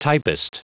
Prononciation du mot typist en anglais (fichier audio)
Prononciation du mot : typist